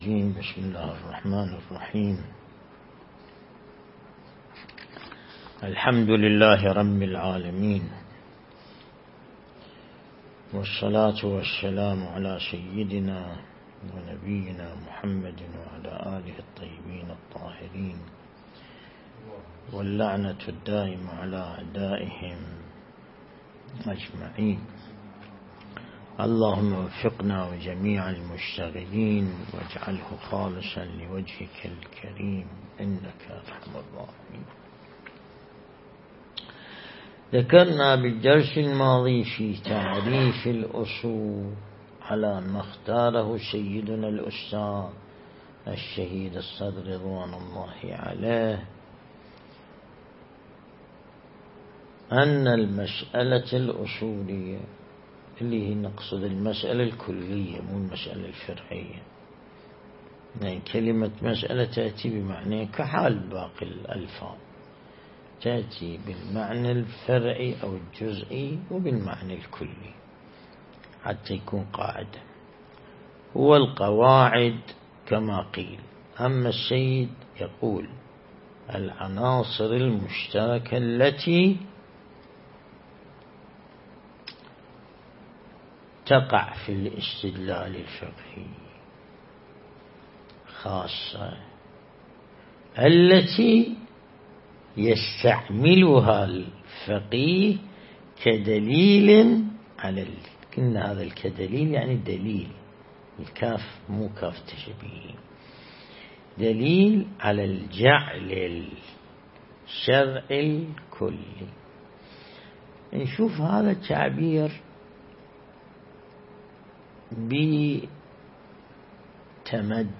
درس البحث الخارج الأصول (19)